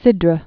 (sĭdrə), Gulf of